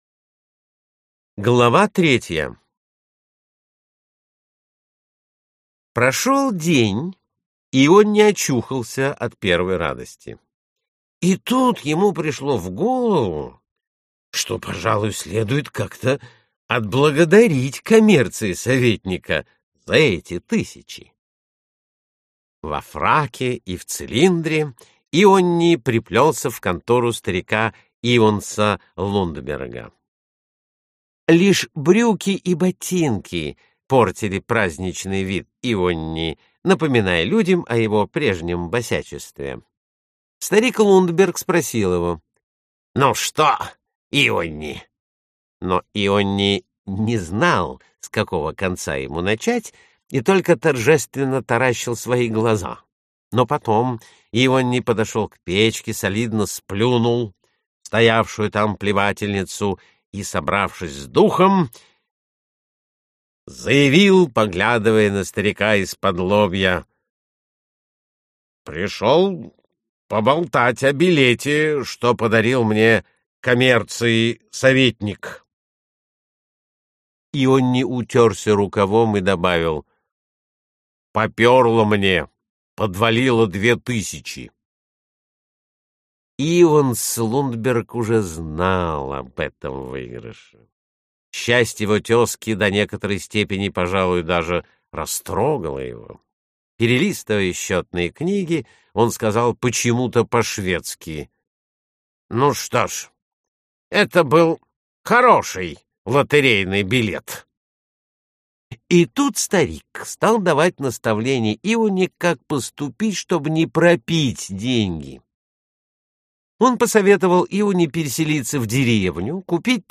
Аудиокнига Воскресший из мертвых | Библиотека аудиокниг